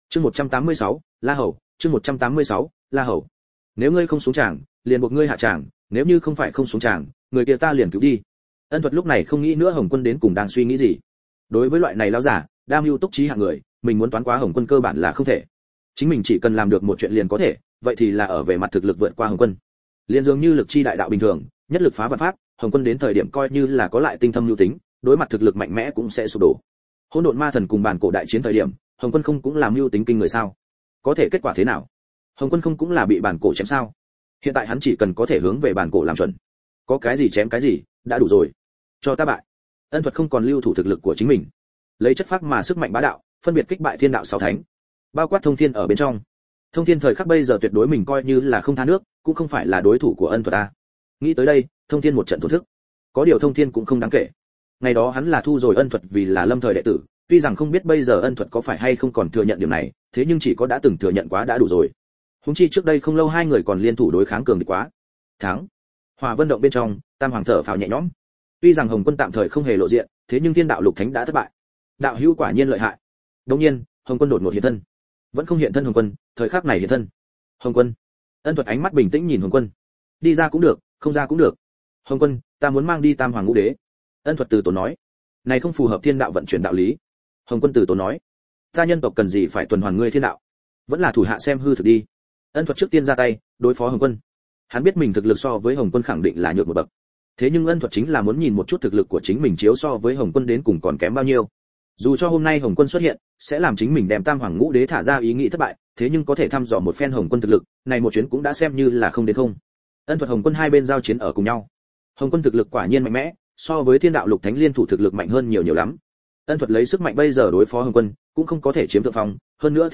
Nghe truyện Audio online Người Ở Hồng Hoang, Bắt Đầu Phục Chế Na Tra Dòng ! Audio Của Tác Giả Trượng Kiếm Thính Vũ Thanh: Sống lại Hồng Hoang, Phong Thần sắp tới. Ân Thuật trở thành Ân Thương vương thất hậu duệ.